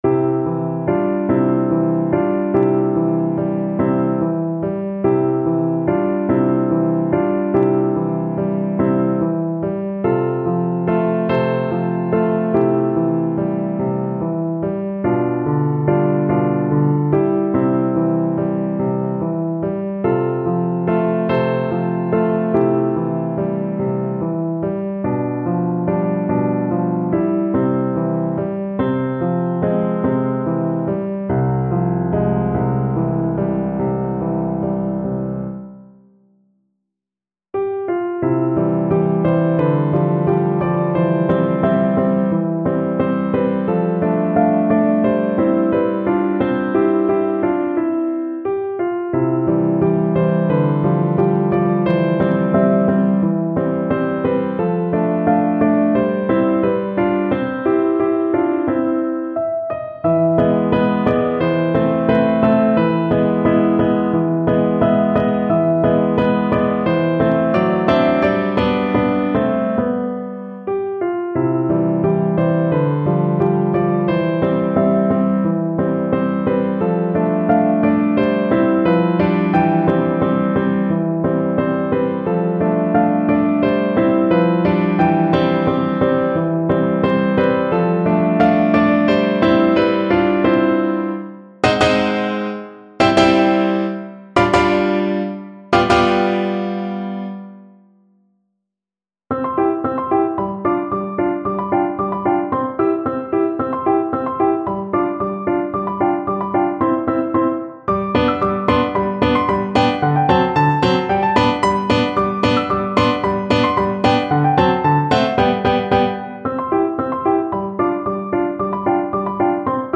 Free Sheet music for Piano
No parts available for this pieces as it is for solo piano.
C major (Sounding Pitch) (View more C major Music for Piano )
3/4 (View more 3/4 Music)
Andante sostenuto (.=48)
Piano  (View more Intermediate Piano Music)
Classical (View more Classical Piano Music)